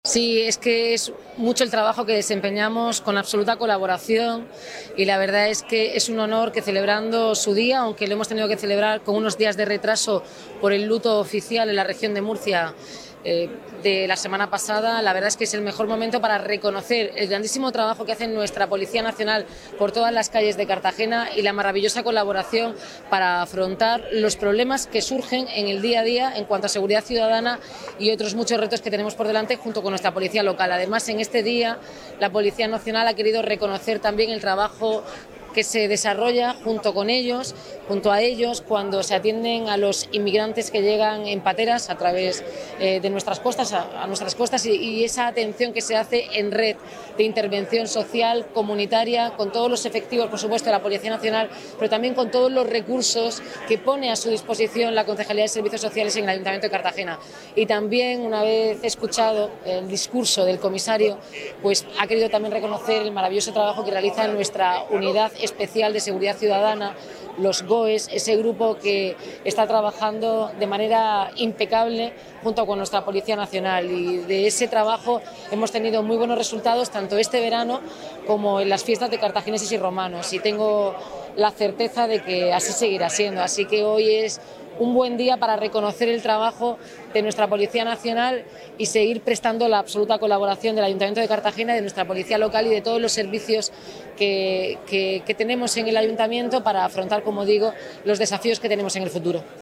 El acto se ha desarrollado en la calle del Carmen con posterioridad tras el luto por la reciente tragedia en Murcia.